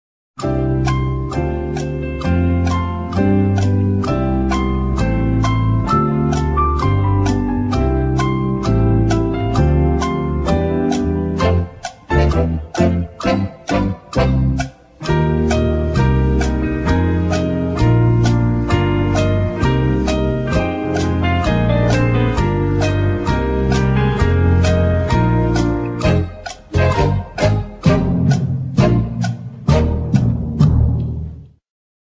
دانلود آهنگ غمگین برای زنگ گوشی خارجی